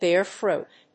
アクセントbéar [prodúce] frúit